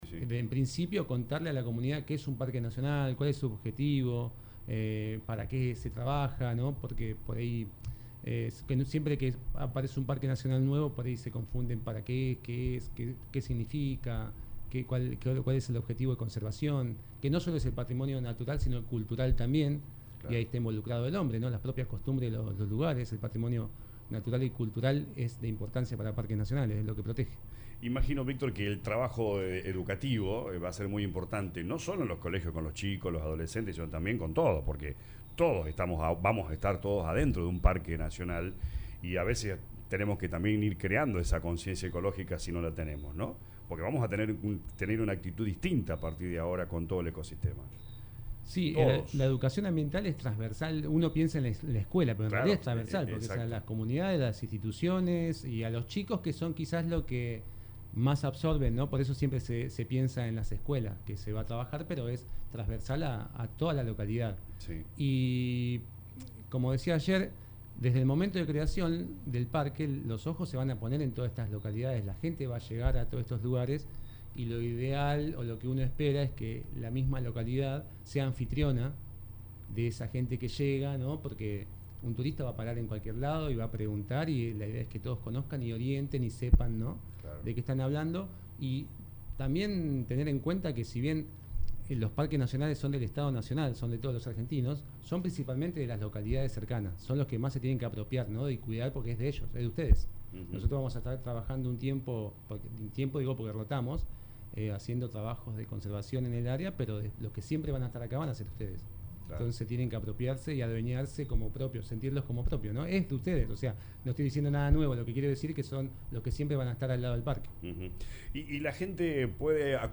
dialogó con LA RADIO 102.9 sobre las responsabilidades y actividades que tendrá a su cargo.